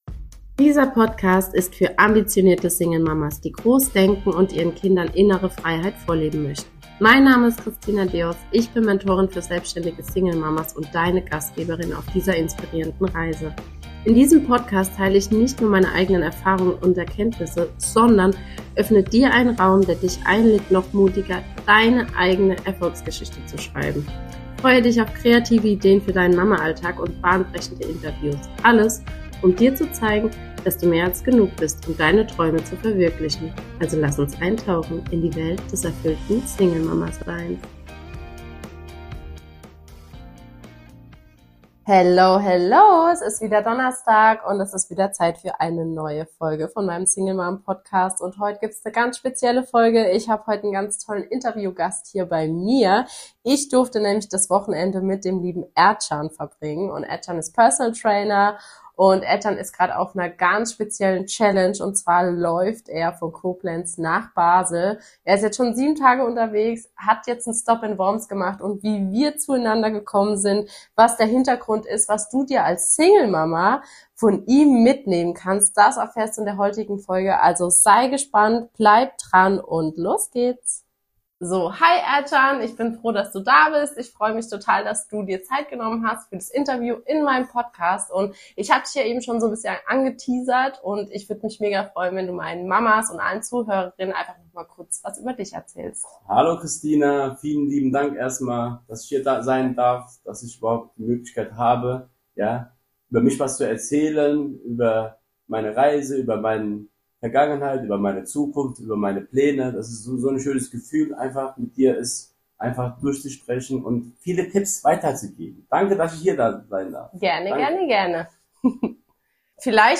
#7 Dein Weg zum Erfolg: Interview